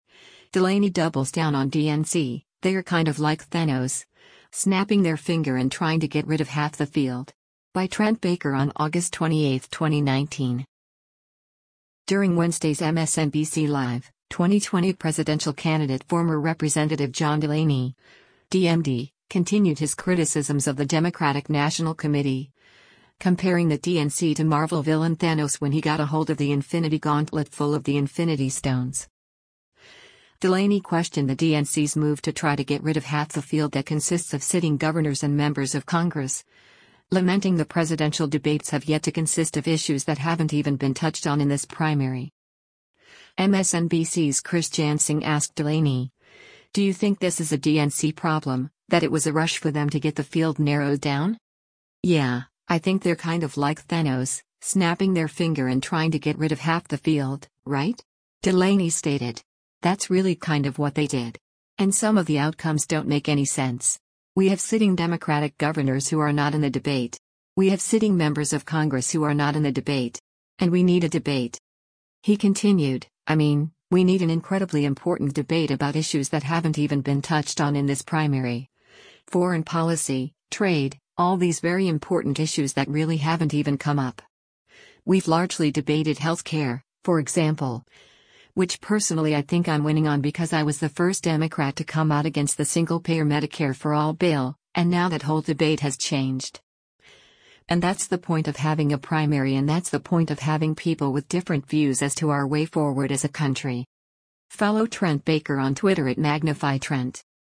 During Wednesday’s “MSNBC Live,” 2020 presidential candidate former Rep. John Delaney (D-MD) continued his criticisms of the Democratic National Committee, comparing the DNC to Marvel villain Thanos when he got a hold of the Infinity Gauntlet full of the Infinity Stones.
MSNBC’s Chris Jansing asked Delaney, “Do you think this is a DNC problem, that it was a rush for them to get the field narrowed down?